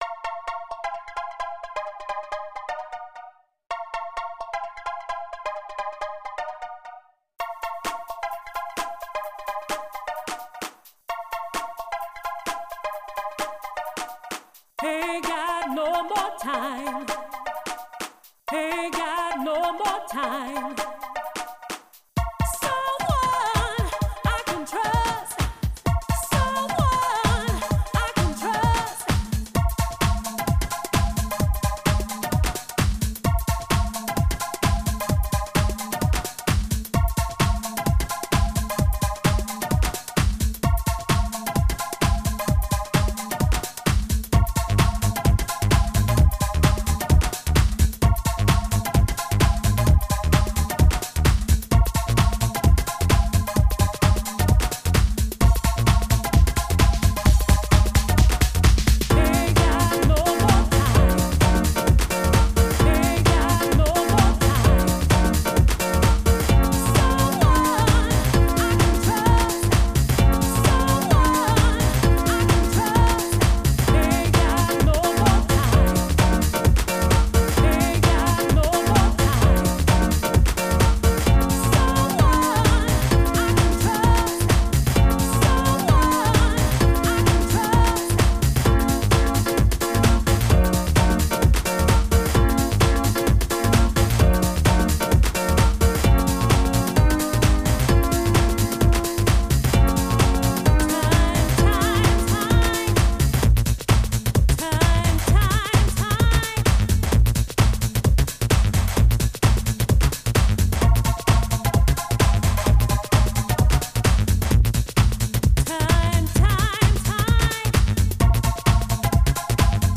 House-Single